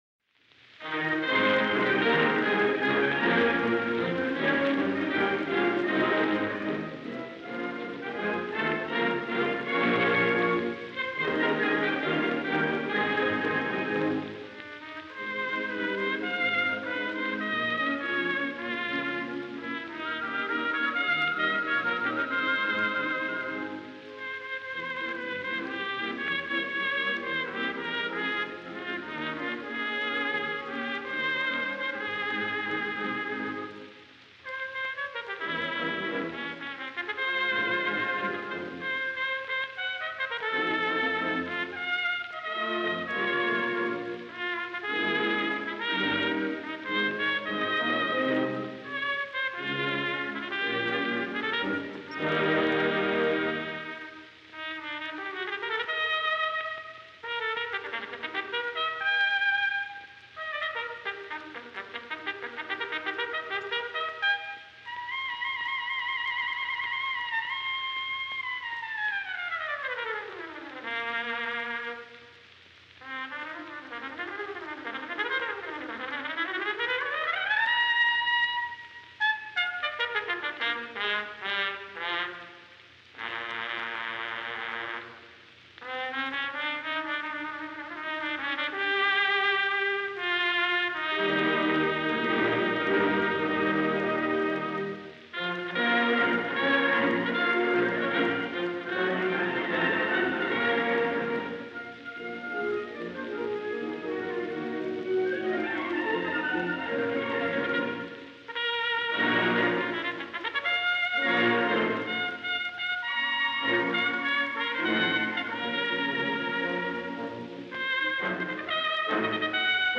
cornet